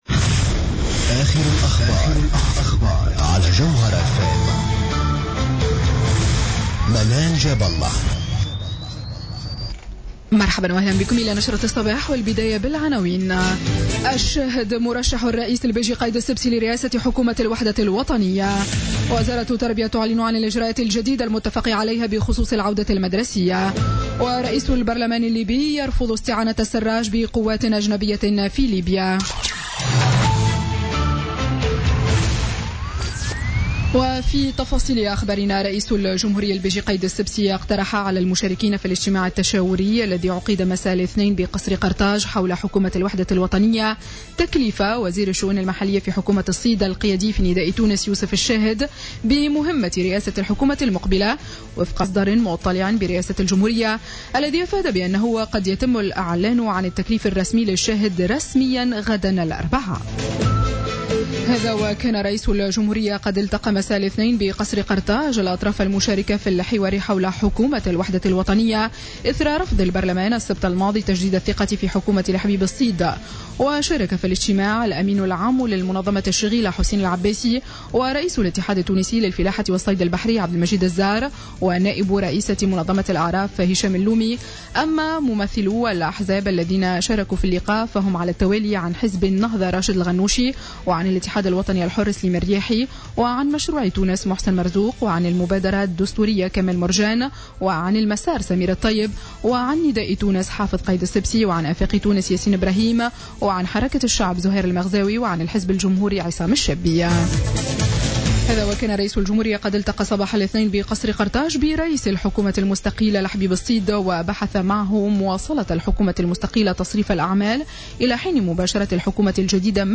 Journal Info 07h00 du mardi 2 aout 2016